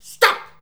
STOP.wav